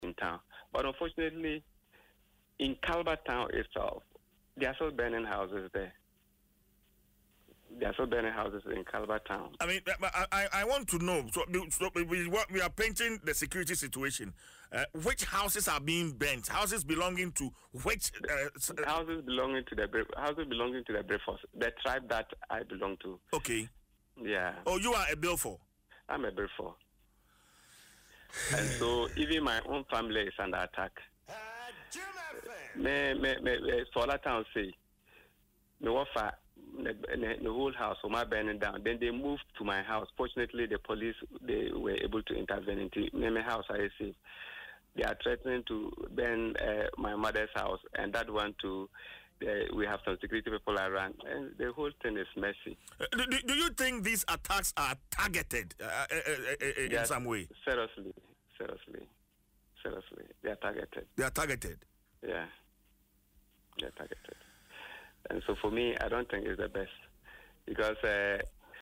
Speaking on Adom FM’s Dwaso Nsem, Mr. Chiwetey revealed that houses in Kalba town continue to be torched in what he described as targeted attacks against members of the Birifor community.